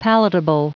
Prononciation du mot palatable en anglais (fichier audio)
palatable.wav